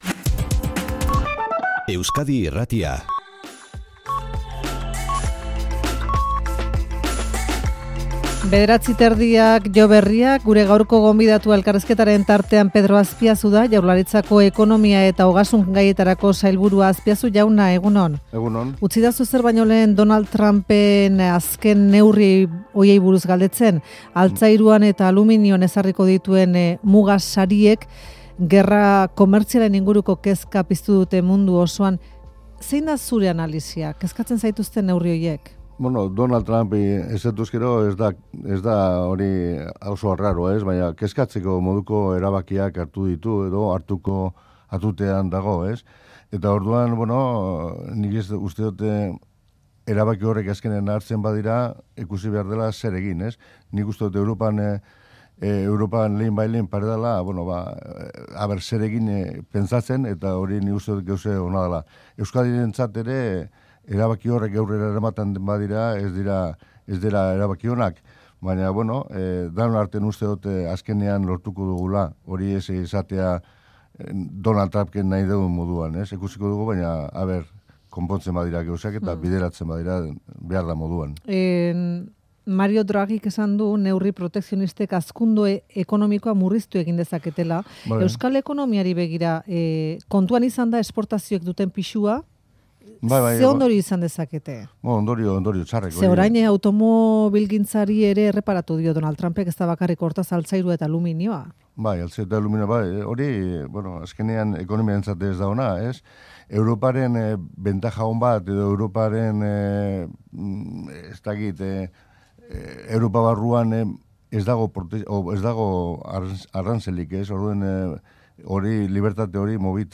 Audioa: Pedro Aspiazu, ogasun eta ekonomia sailburua, Euskadi Irratiko Faktorian.